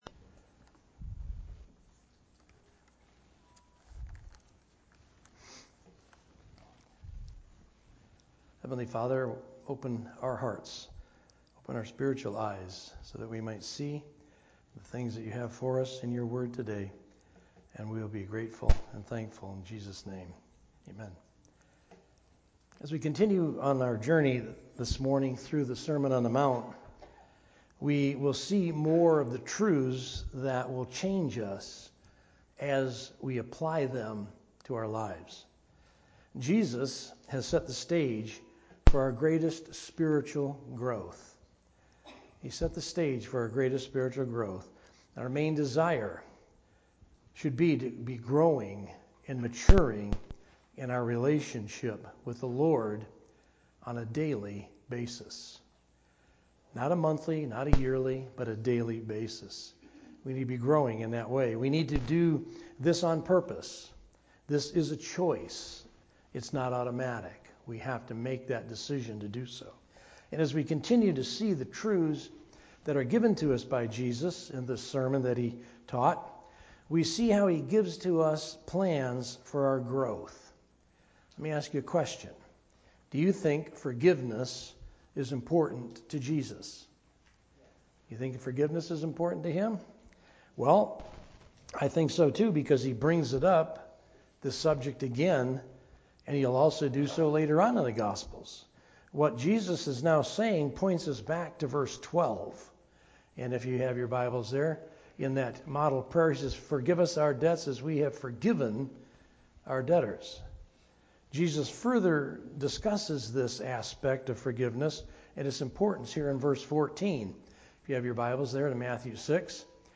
A message from the series "Sunday Morning - 11:00."